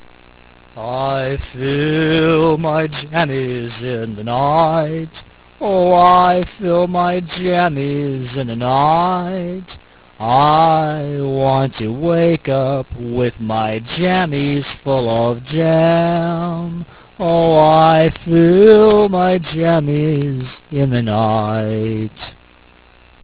Click this to hear me sing (36K).